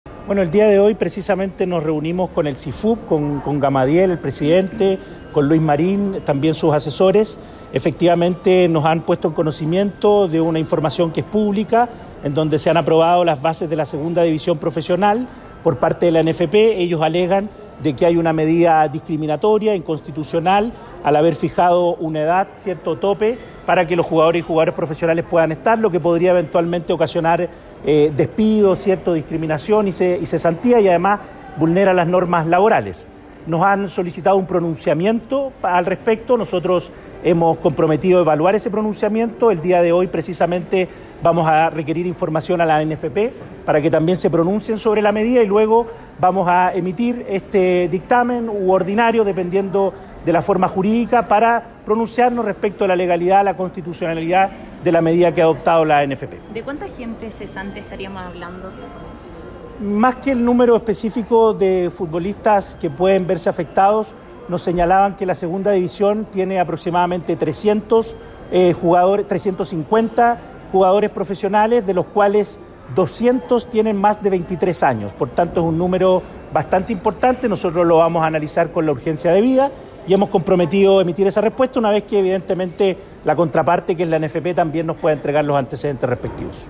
Pablo Zenteno, Director del Trabajo